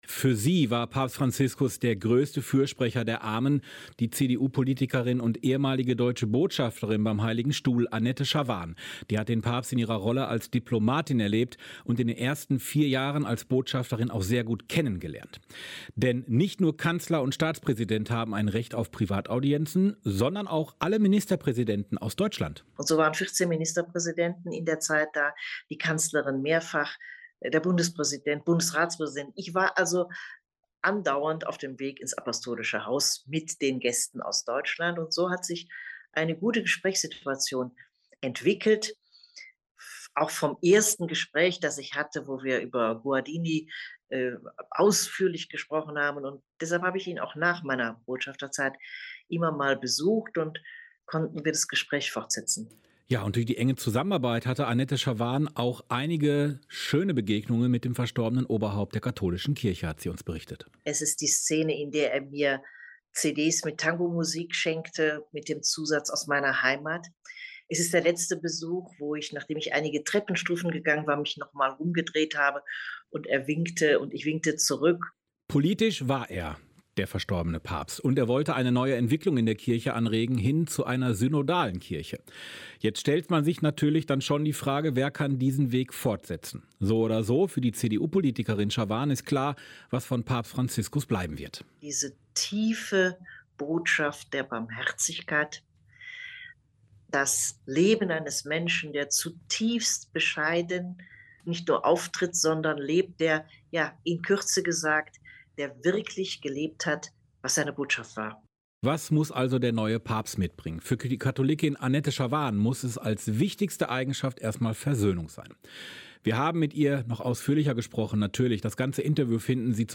Ein Interview mit Annette Schavan (Ehemalige Botschafterin am Heiligen Stuhl, ehemalige deutsche Bundesministerin für Bildung und Forschung)